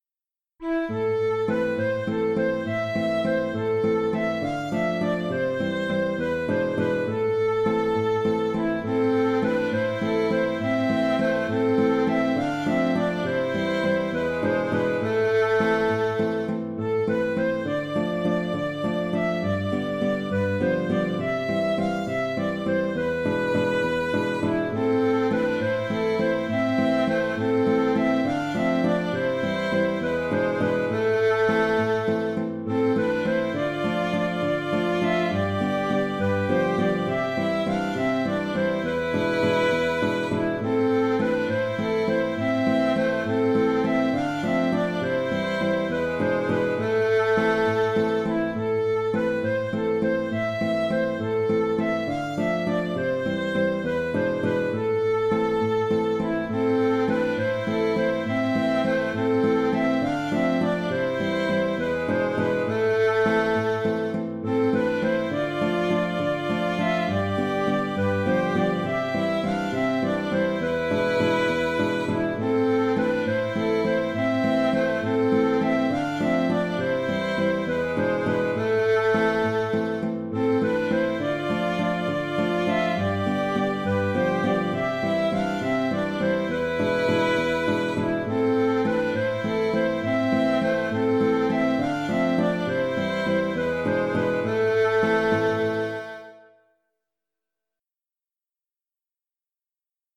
Sous ta fenêtre (Valse) - Musique folk
Sa couleur un peu mélancolique me fait plutôt choisir la Suède.